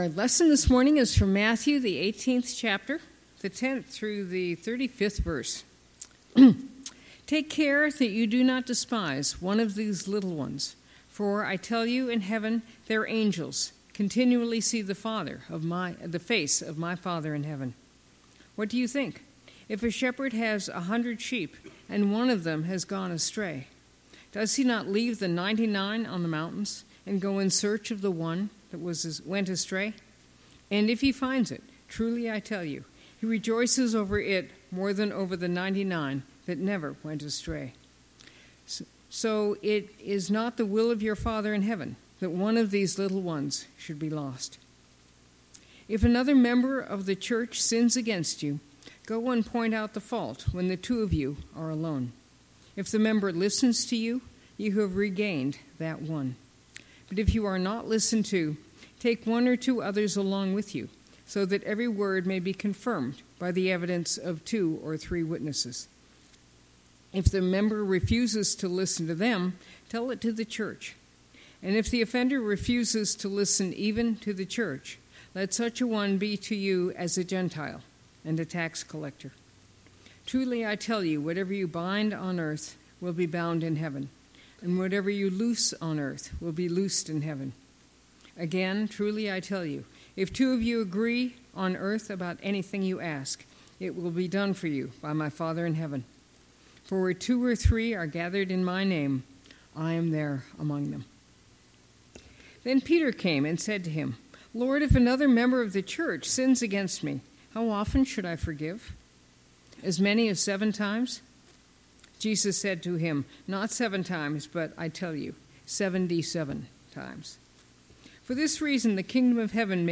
Passage: Matthew 18:10-35 Service Type: Sunday Morning